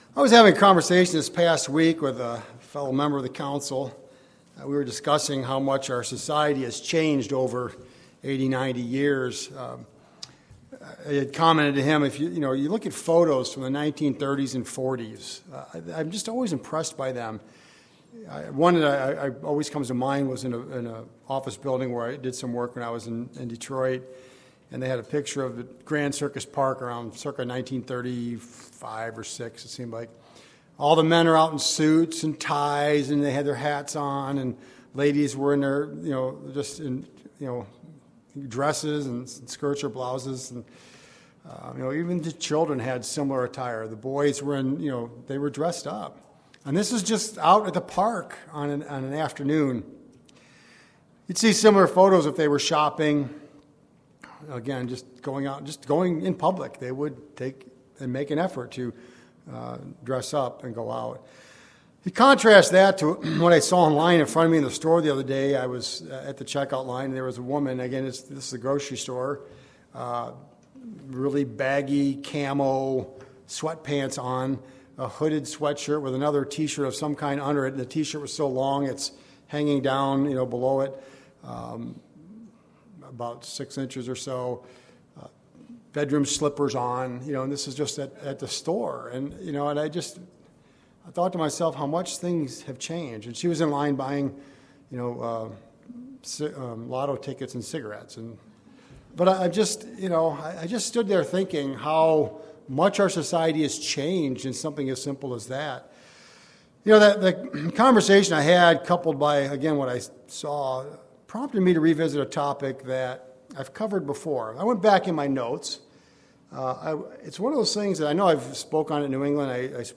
Biblical instructions on how God's people should adorn themselves. Through this sermon, we'll see that the matter is more than physical.